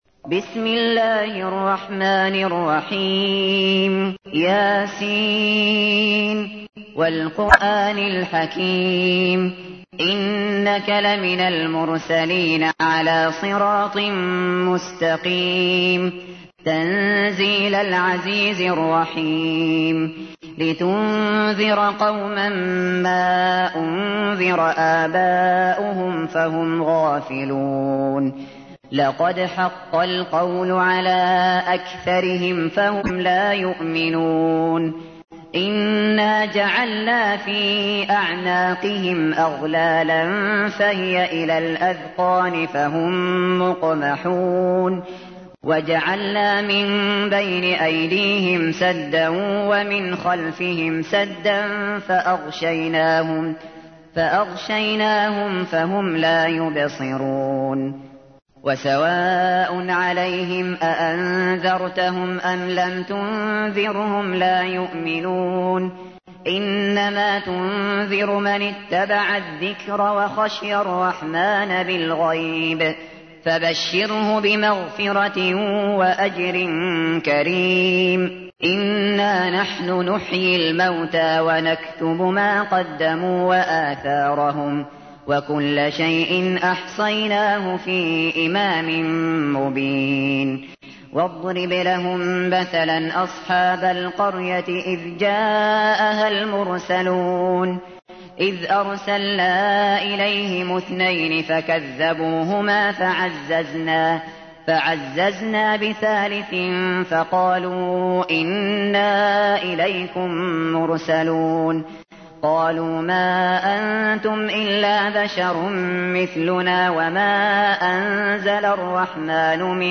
تحميل : 36. سورة يس / القارئ الشاطري / القرآن الكريم / موقع يا حسين